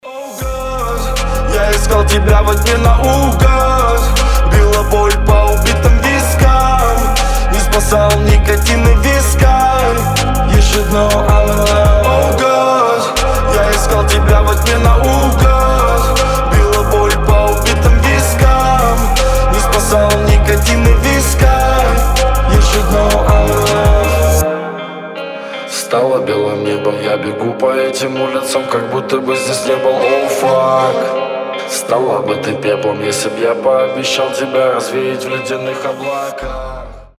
• Качество: 320, Stereo
гитара
мужской вокал
атмосферные
басы
электрогитара
Alternative Rap